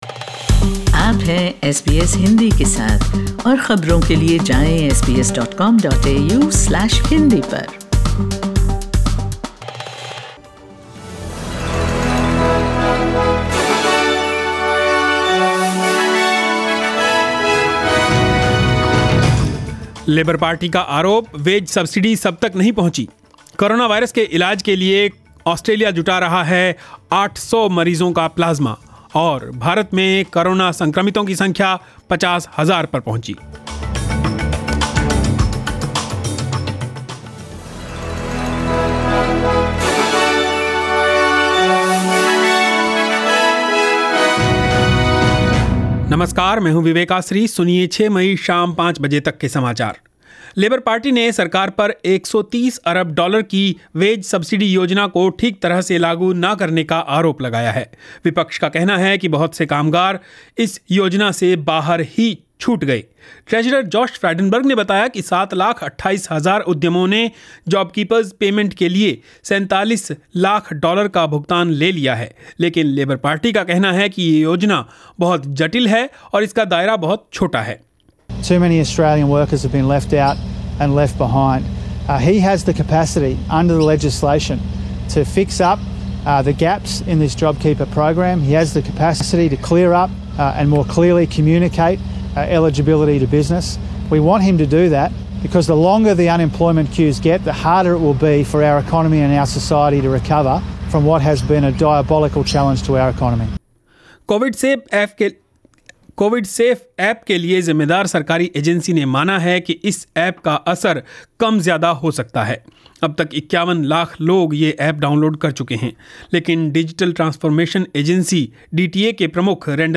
News in Hindi 06 May 2020